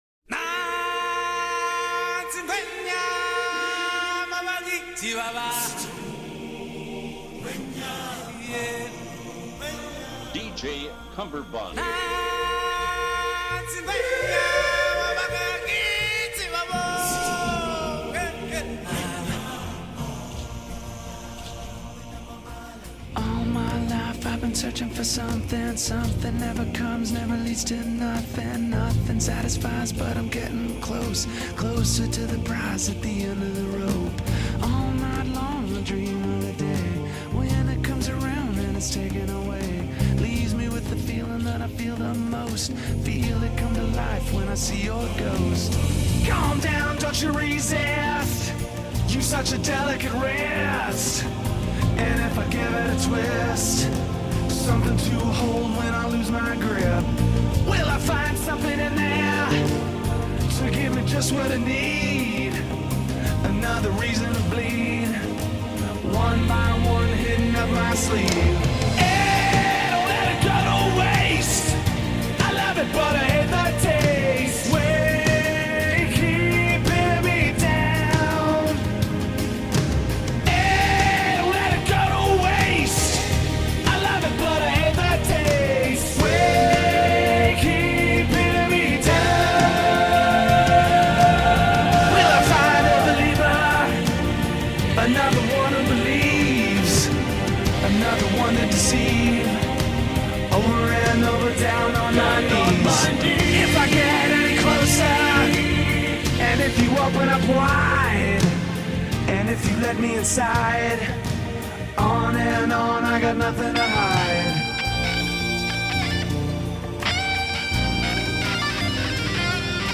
Hay un mashup